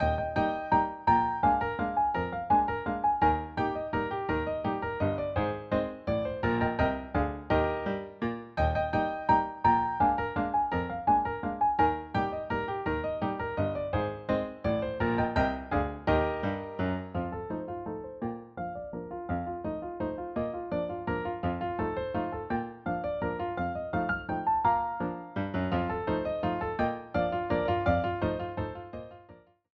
Klavier Solo
Volksmusik; Stubenmusik; Ländler; Klaviermusik